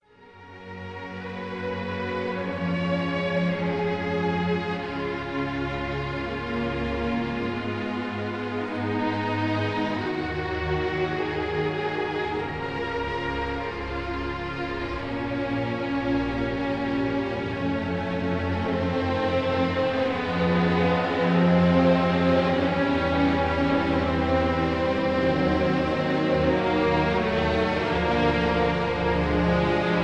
Andante malincolico (Melancholic)
1956 live performance